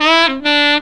COOL SAX 4.wav